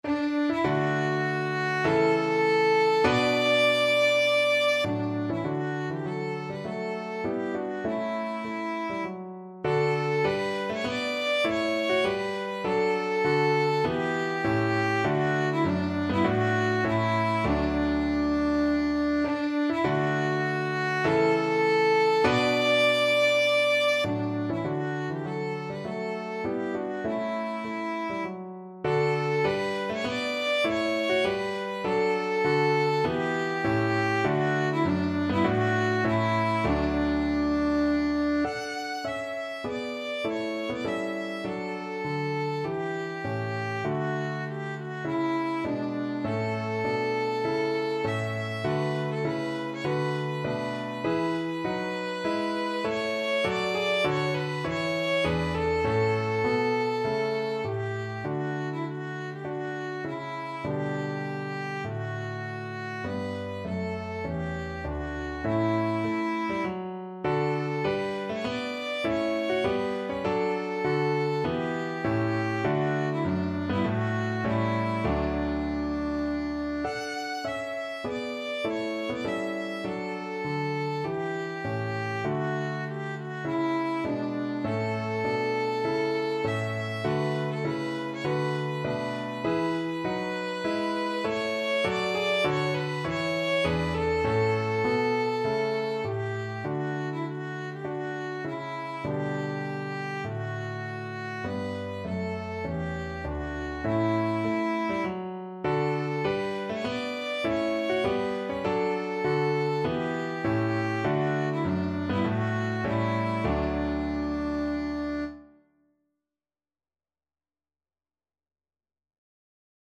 4/4 (View more 4/4 Music)
Classical (View more Classical Violin Music)